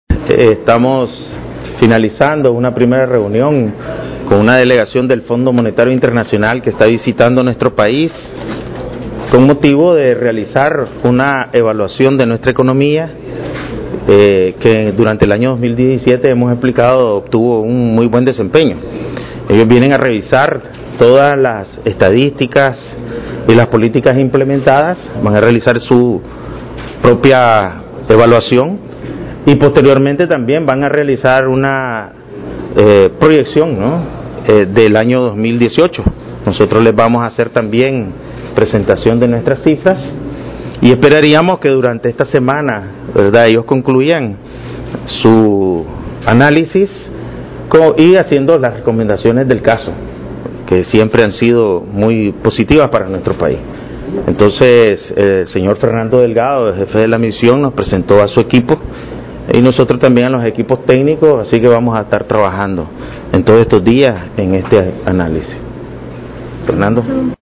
Declaraciones a Medios del Presidente del BCN, Ovidio Reyes R. (amr) (